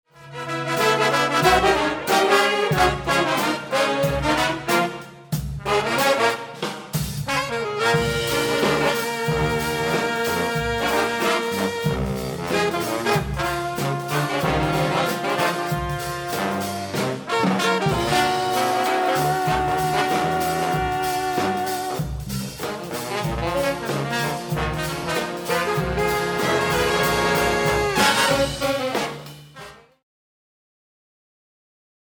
Voicing: Trombone Ensemble